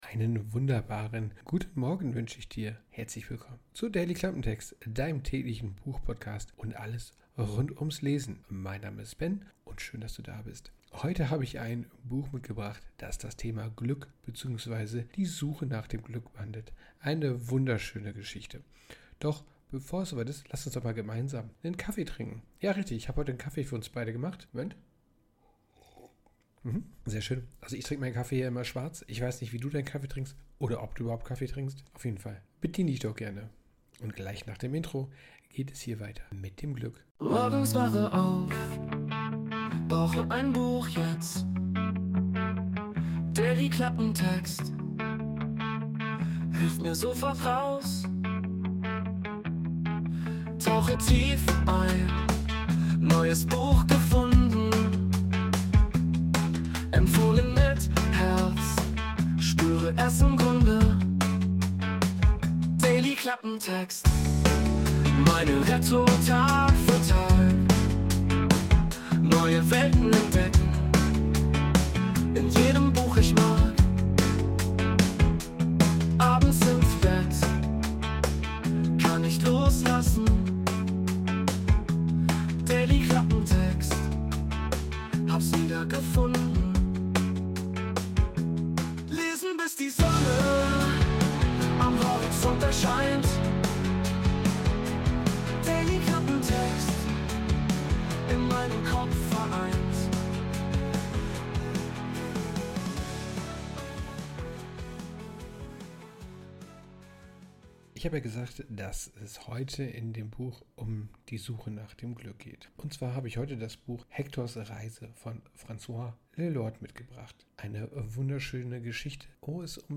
Instrumental Melody
Intromusik: Wurde mit der KI Suno erstellt.